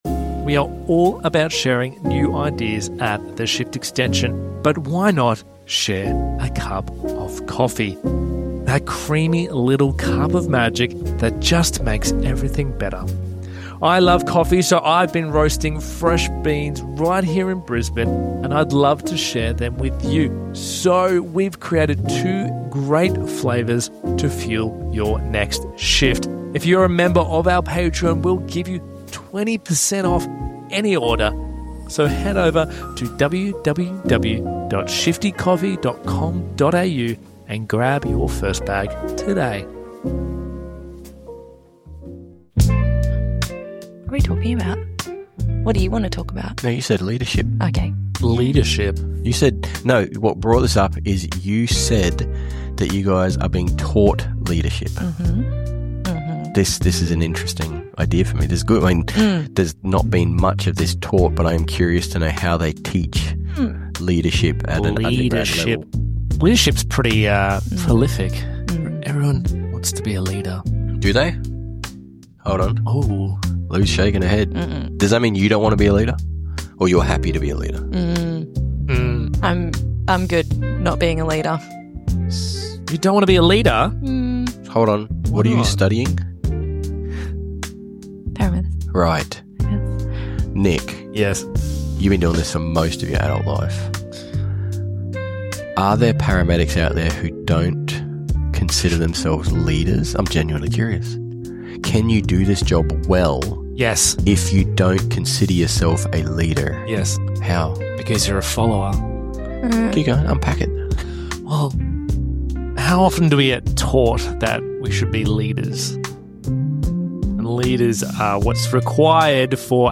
This was a topic that came up over coffee. We hit record and wanted to see where the conversation would flow.